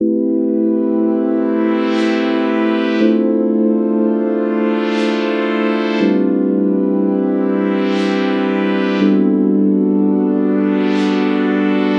周围环境点击
描述：轻轻敲打玻璃瓶盖，然后在FL工作室进行编辑。
Tag: 环境 单击 瓶盖